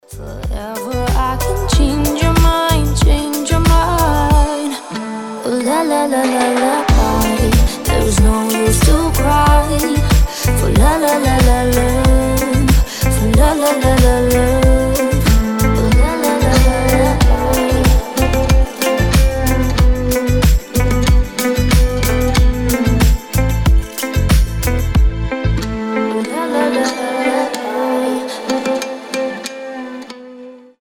красивые
мелодичные
спокойные
красивый женский голос